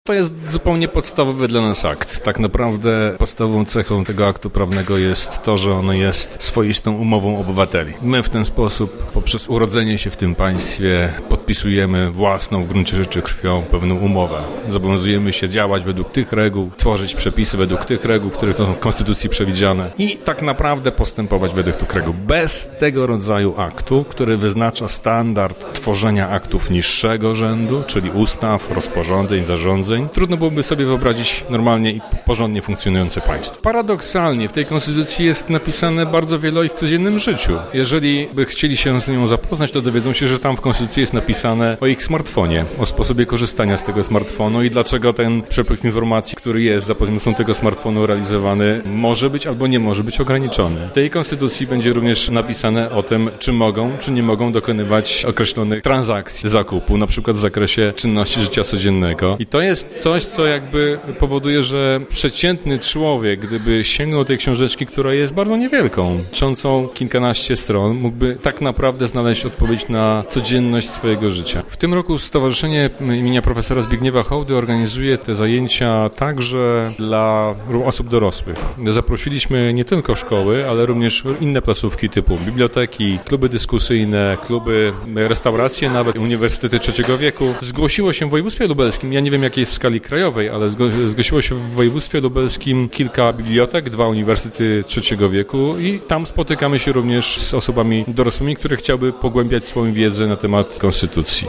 adwokat.mp3